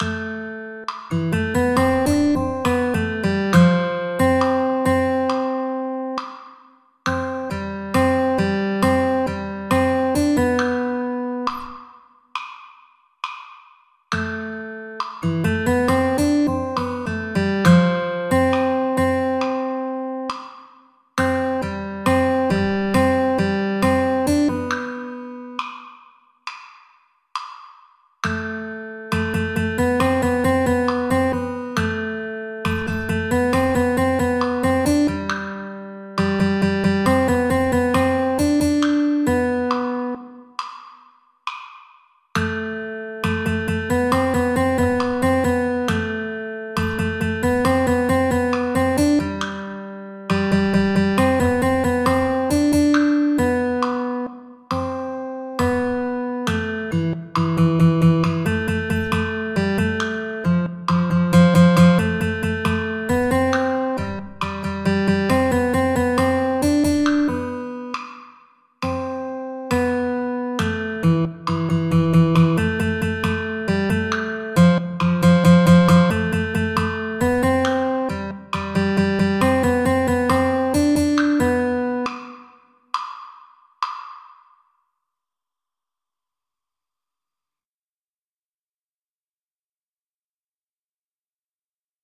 para flauta, y xilófonos.